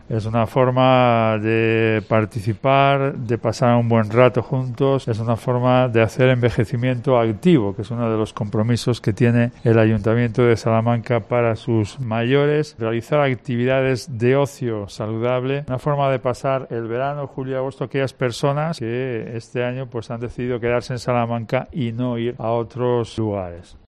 El Alcalde de Salamanca cuenta el objetivo de estos programas